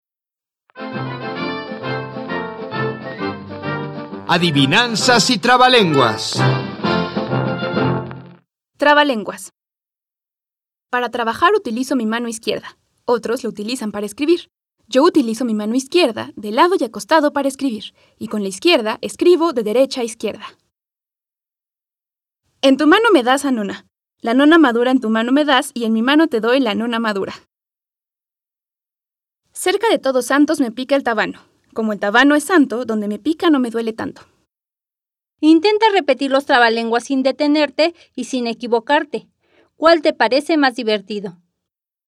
Audio 37. Trabalenguas
144_Trabalenguas.mp3